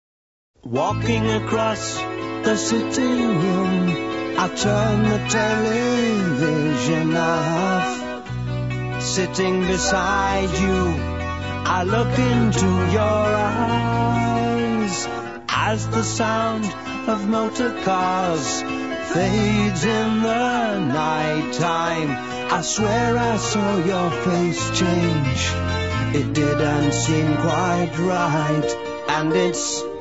assorted percussion
cello
flute
guitar 12 string
guitar electric
• registrazione sonora di musica